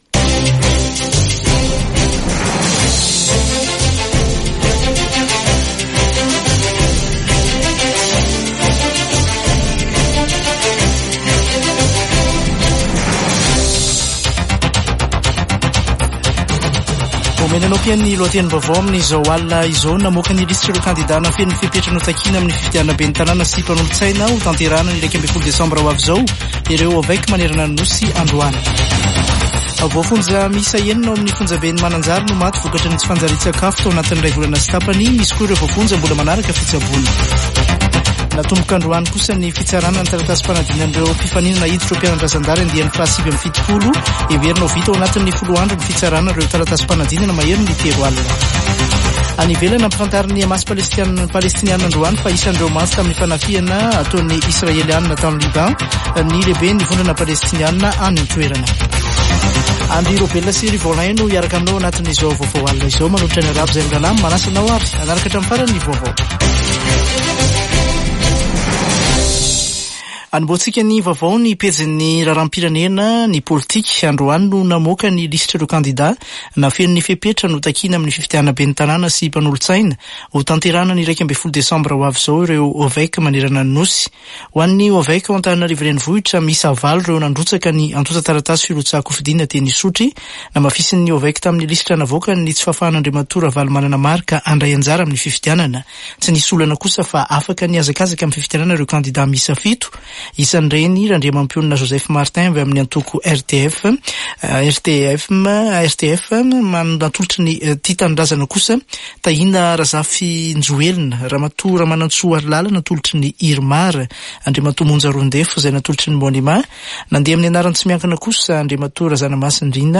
[Vaovao hariva] Alatsinainy 30 septambra 2024